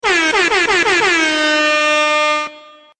MLG AIR HORN!!!!!!!!!!!
mlg-air-horn-soundbuttonsboard.net_.mp3